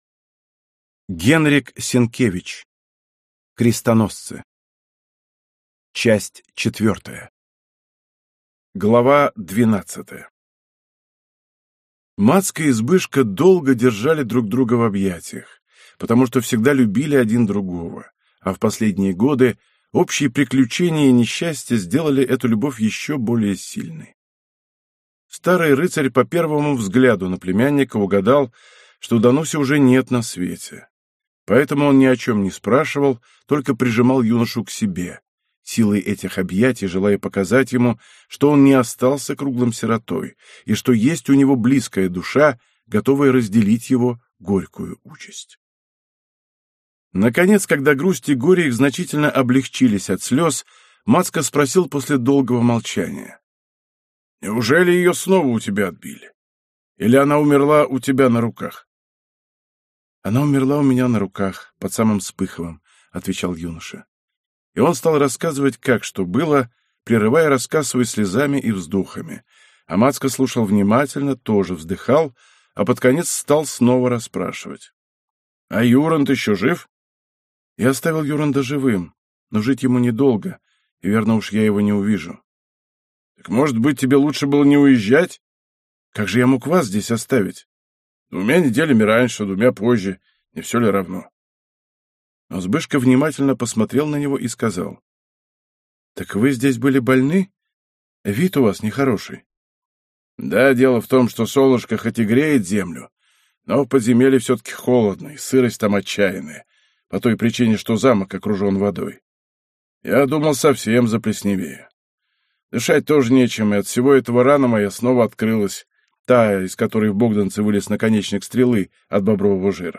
Аудиокнига Крестоносцы. Часть 4 | Библиотека аудиокниг